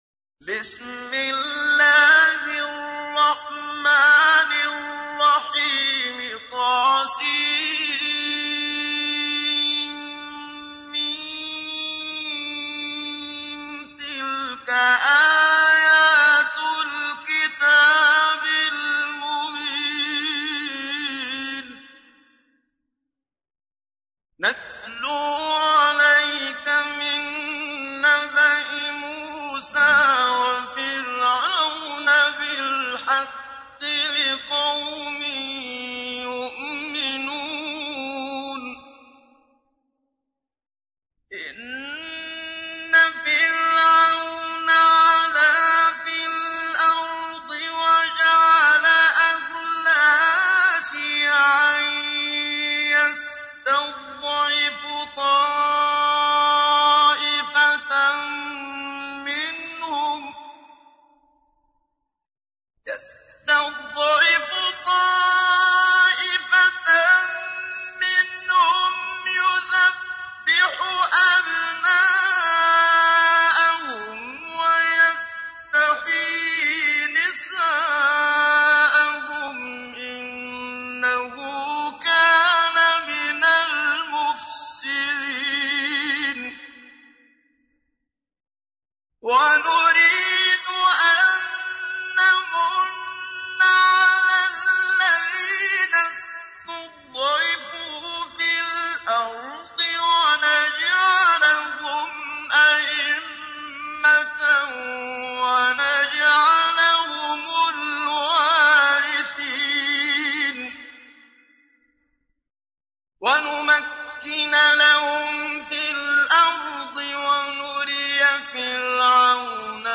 تحميل : 28. سورة القصص / القارئ محمد صديق المنشاوي / القرآن الكريم / موقع يا حسين